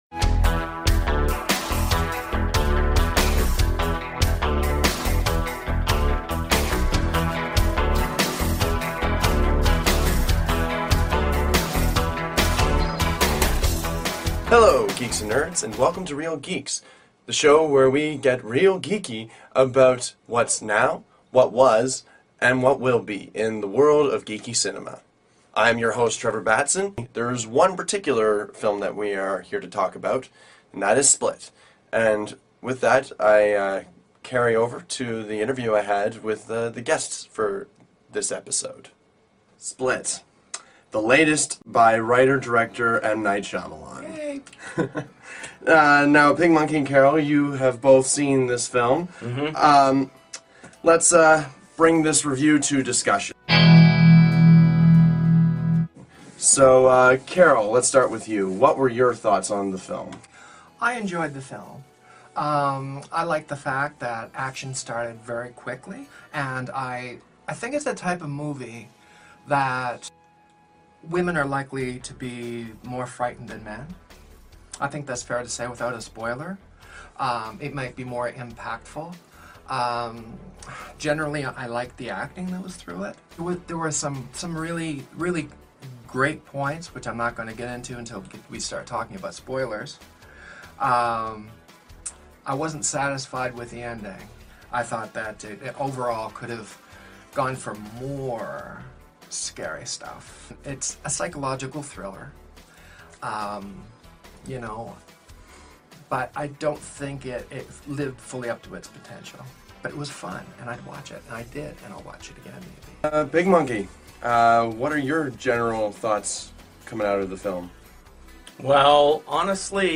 Originally recorded on January 30, 2017 in Halifax, NS, Canada